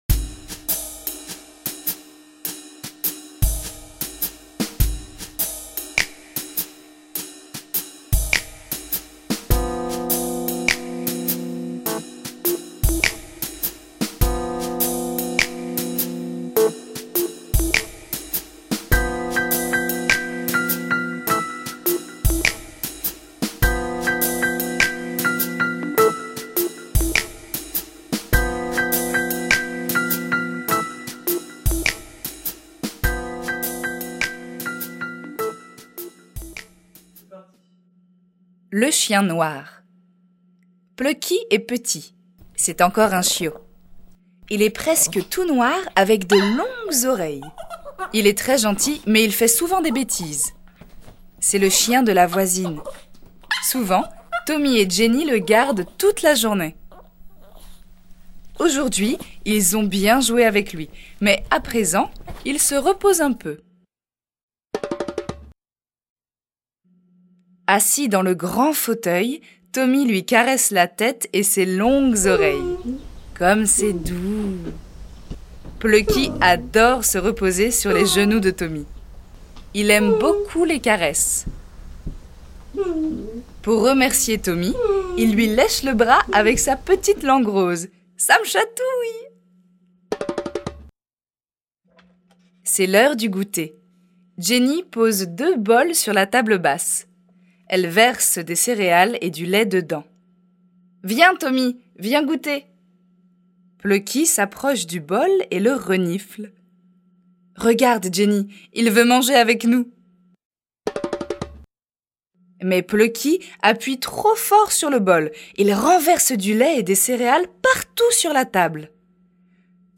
toutes deux comédiennes professionnelles.
Extrait en français